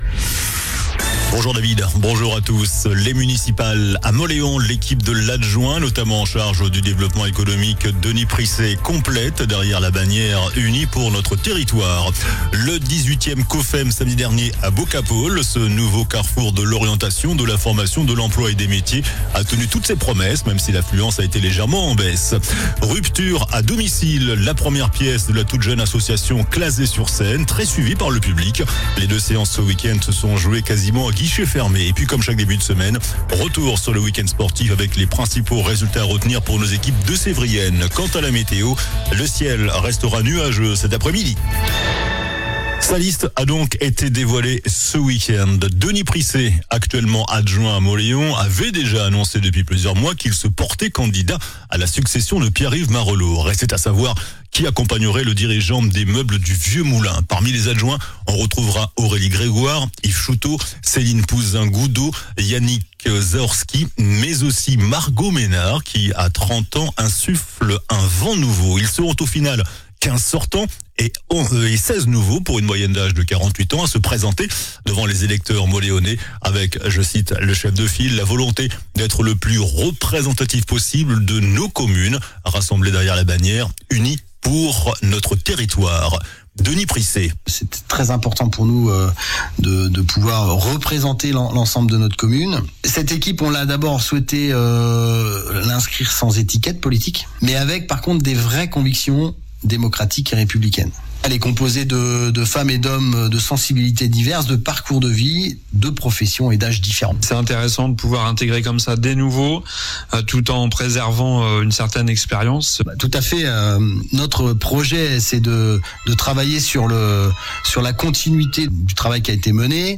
JOURNAL DU LUNDI 02 FEVRIER ( MIDI )